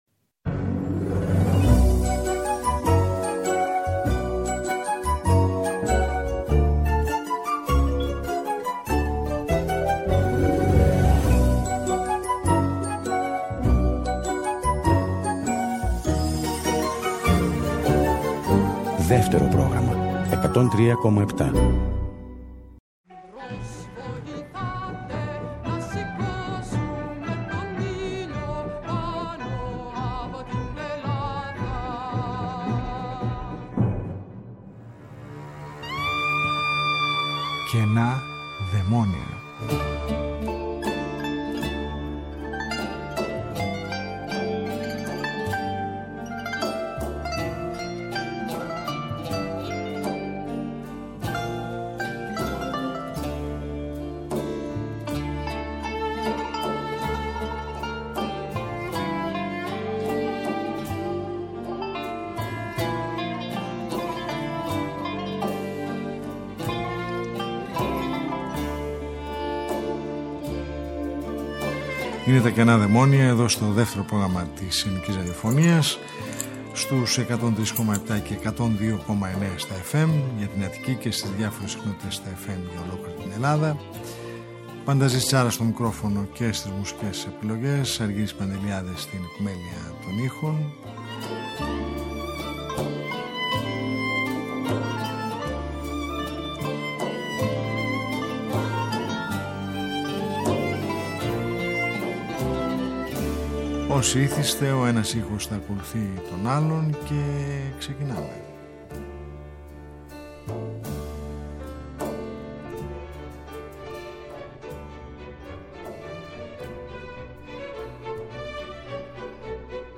Μια ραδιοφωνική συνάντηση κάθε Σάββατο στις 10:00 που μας οδηγεί μέσα από τους ήχους της ελληνικής δισκογραφίας του χθες και του σήμερα σε ένα αέναο μουσικό ταξίδι.